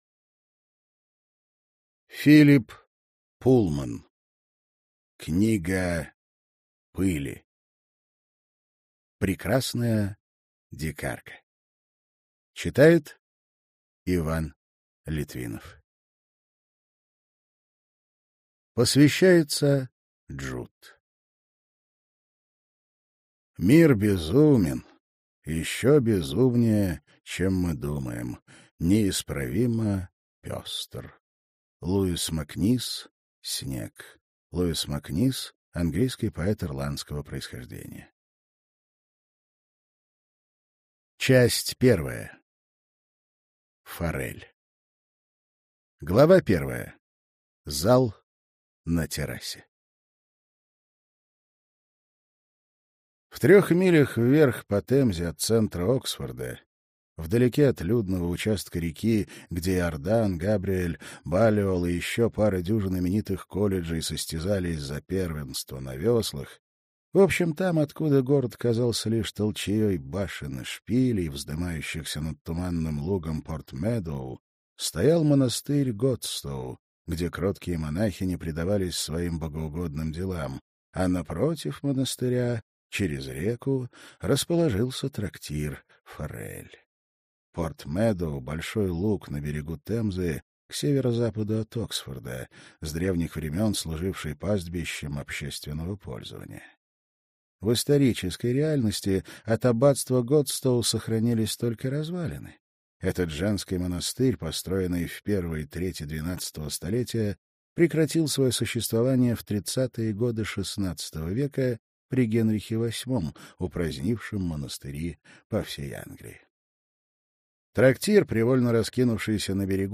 Аудиокнига Книга Пыли. Прекрасная дикарка - купить, скачать и слушать онлайн | КнигоПоиск